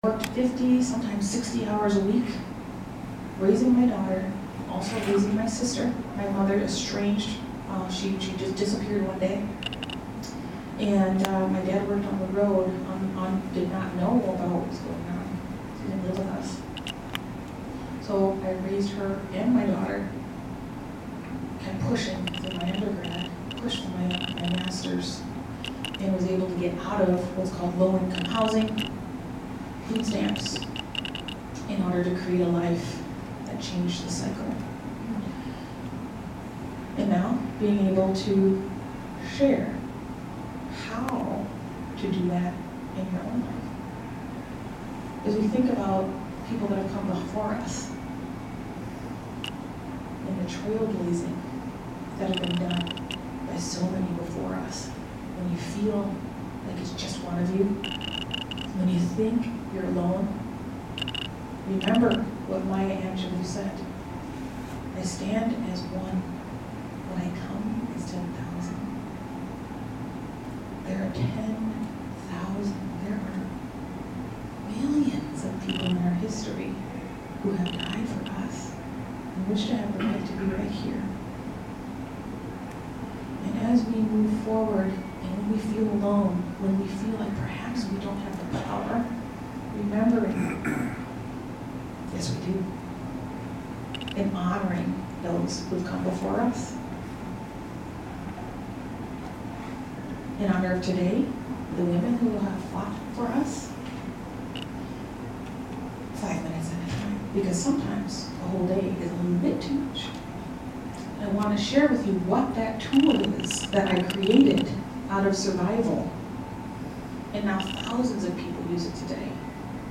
speaks during Fort McCoy's 2022 Women's Equality Day observance, Part II